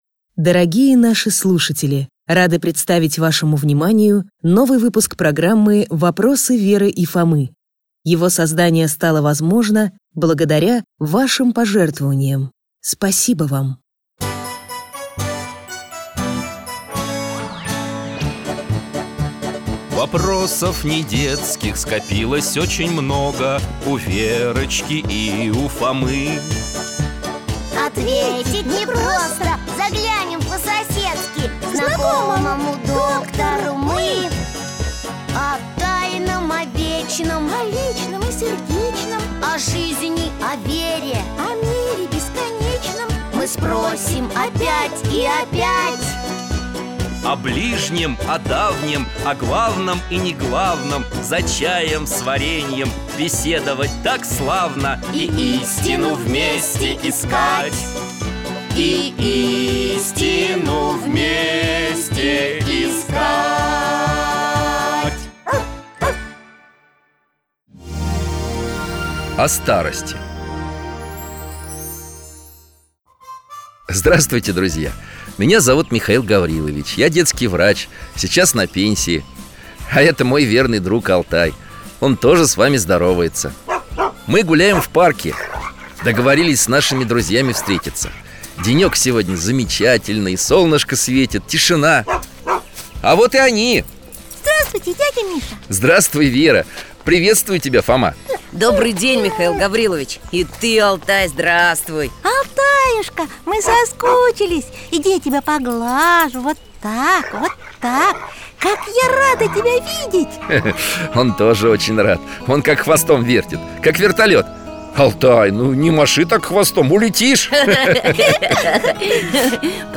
Непростые вопросы задают любознательные ребята, брат и сестра Верочка и Фома, своему старшему другу Михаилу Гавриловичу.
В поисках ответов герои аудиосериала отправятся в увлекательное путешествие по времени и пространству.